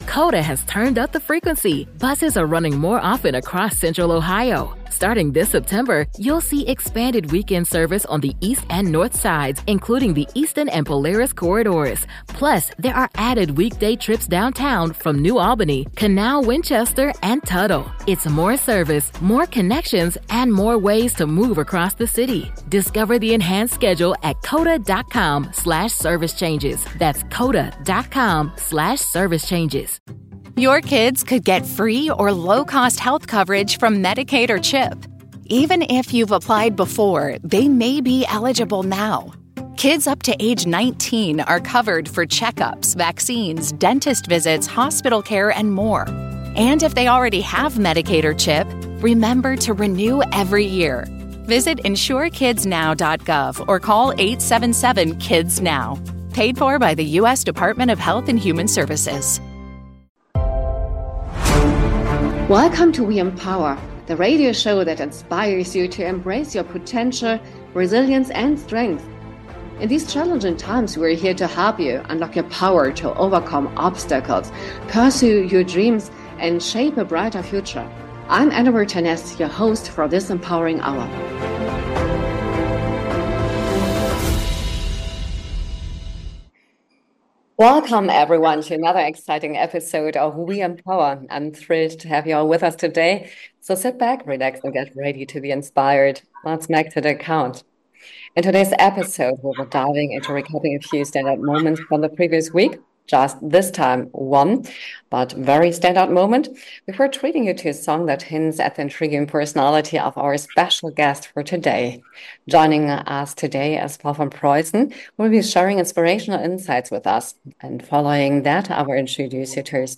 Featuring interviews with impressive female personalities across professions and discussions on women-led businesses, the show celebrates pioneers, especially female pioneers in history, science, art, and culture. It also explores self-development, mental health, and wellbeing, showcasing the most inspiriting books on these topics.